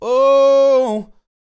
MOANIN 110.wav